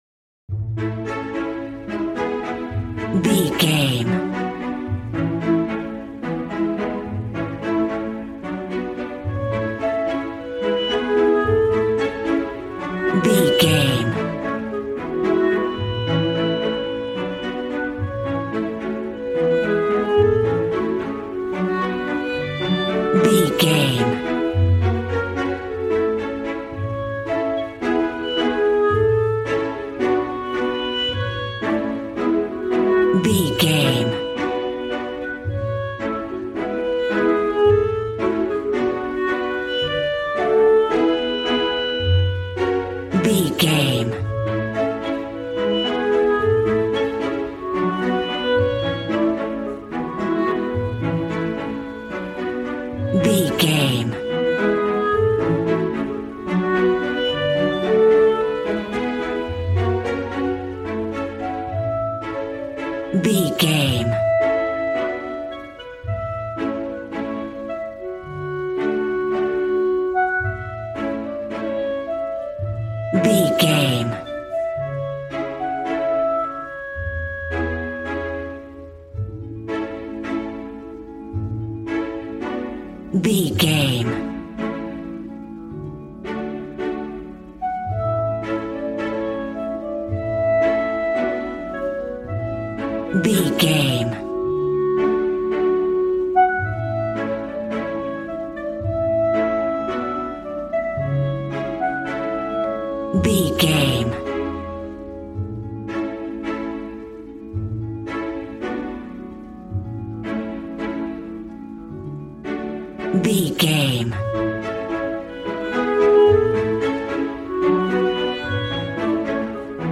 A warm and stunning piece of playful classical music.
Regal and romantic, a classy piece of classical music.
Ionian/Major
regal
piano
violin
strings